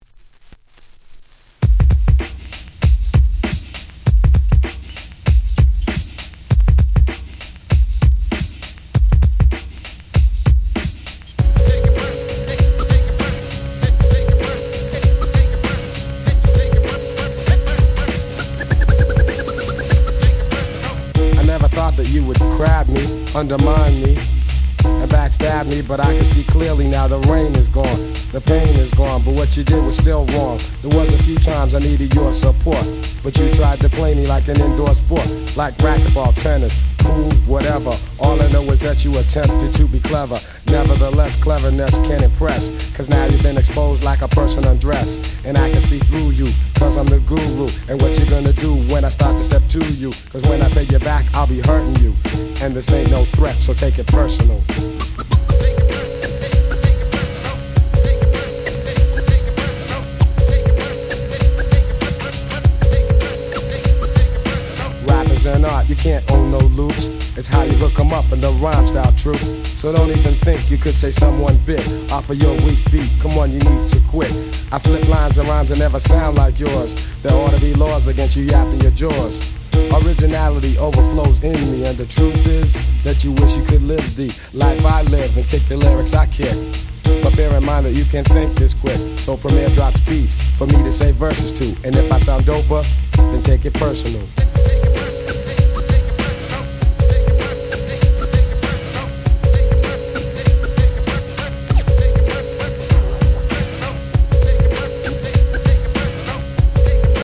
92' Super Hip Hop Classics !!
ギャングスター プリモ プレミア ナイスアンドスムース 90's Boom Bap ブーンバップ